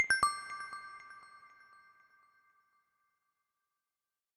delete_timeline.ogg